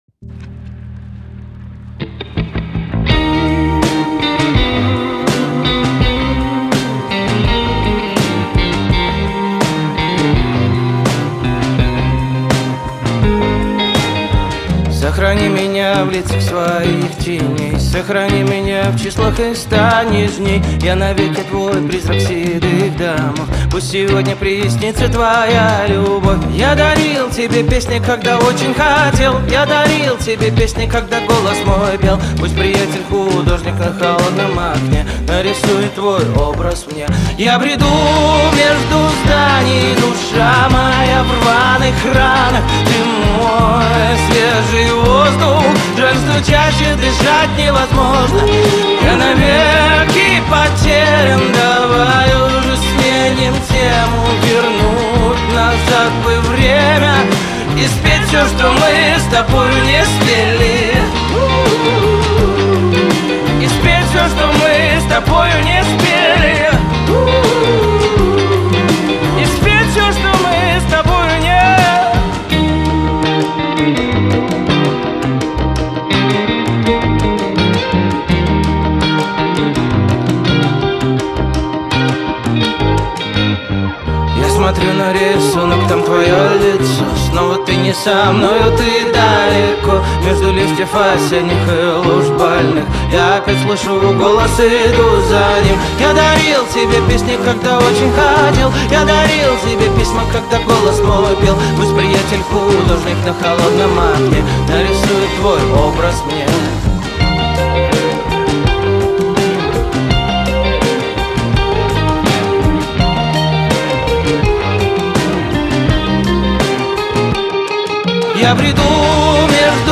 Категории: Русские песни, Инди, Поп.